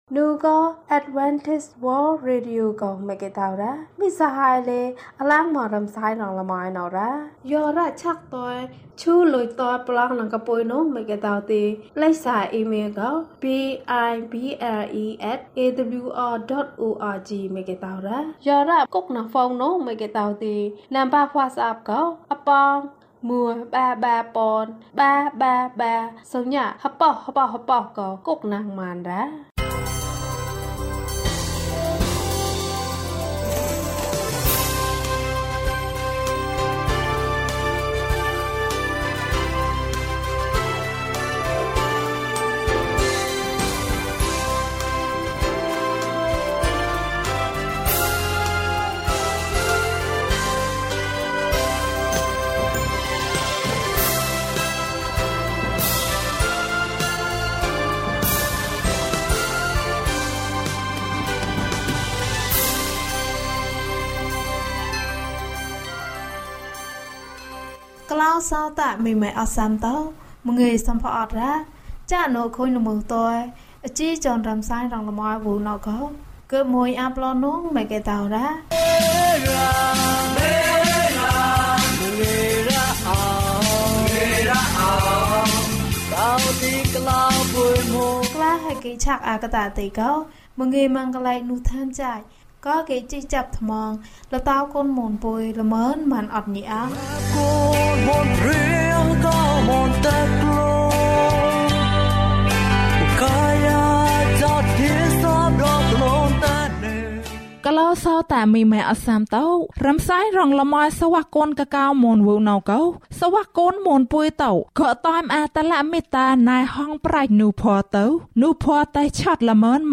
အိုဘုရားသခင်၊ ကိုယ်တော်၏လက်ဖြင့် အကျွန်ုပ်ကို တို့တော်မူပါ။ ကျန်းမာခြင်းအကြောင်းအရာ။ ဓမ္မသီချင်း။ တရားဒေသနာ။